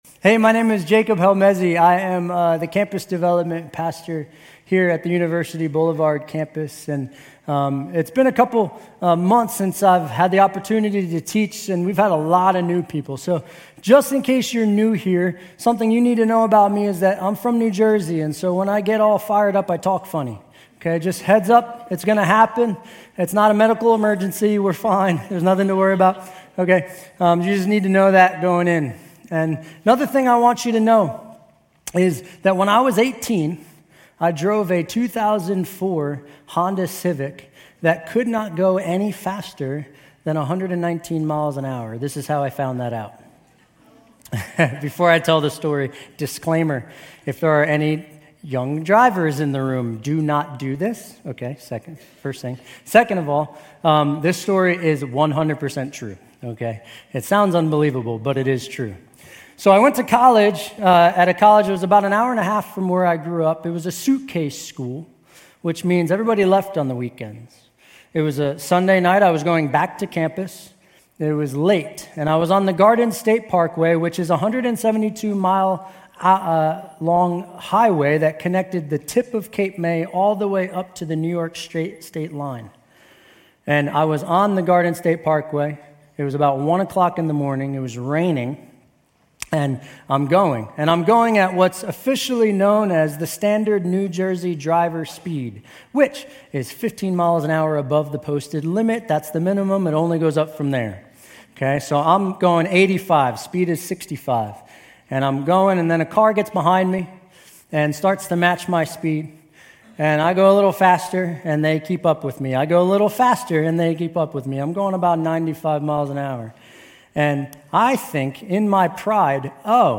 Grace Community Church University Blvd Campus Sermons Genesis 11 - Tower of Babel Oct 07 2024 | 00:37:17 1x 00:00 / 00:37:17 Subscribe Share RSS Feed Share Link Embed